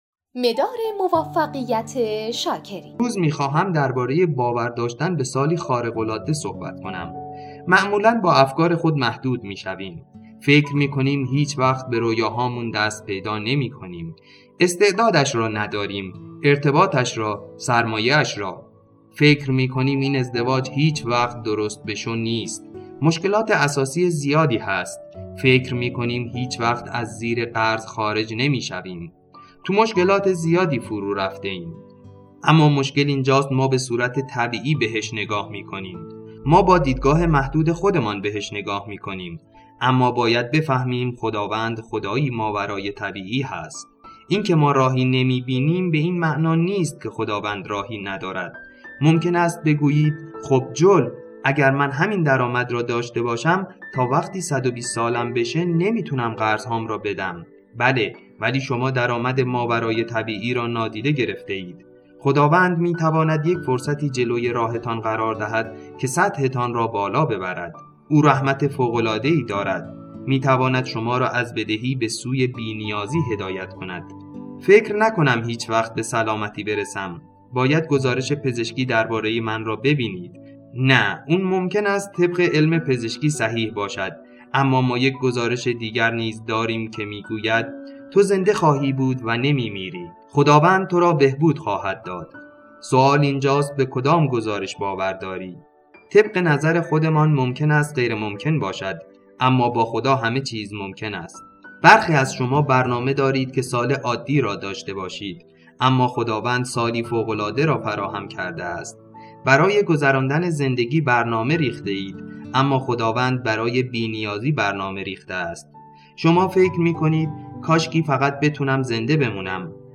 امسال سال تو هست قسمتی از سخنرانی جول اوستین دوبله فارسی